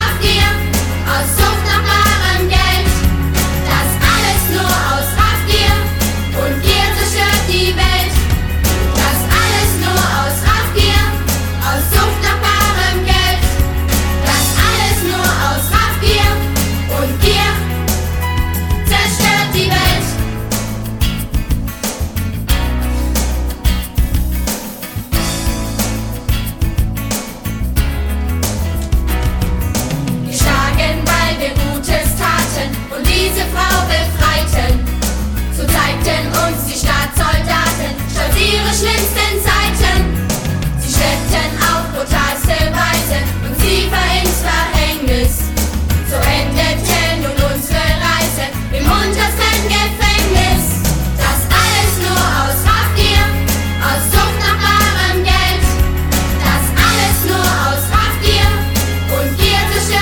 Ein Musical für die ganze Familie
Mit fetzigen Liedern und modernen Arrangements.
Kinderlieder